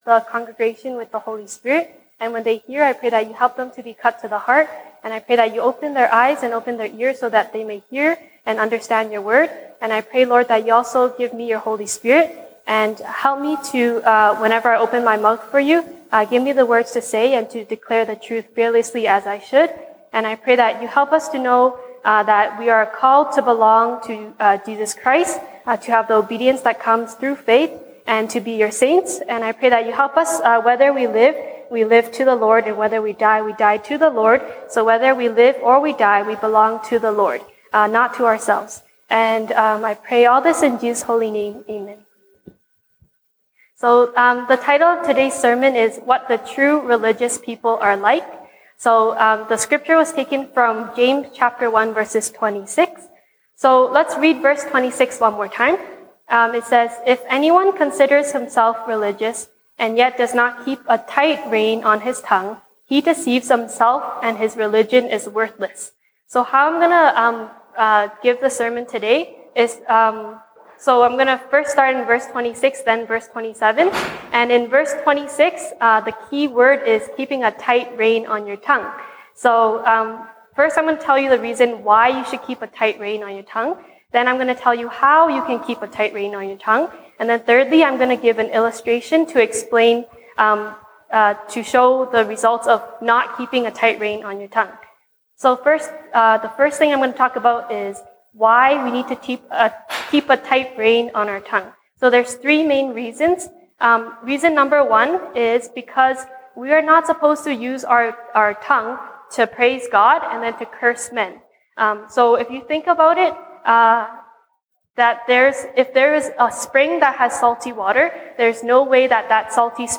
西堂證道 (英語) Sunday Service English: What the True Religious People are Like
Passage: 雅各書 James 1:26-27 Service Type: 西堂證道 (英語) Sunday Service English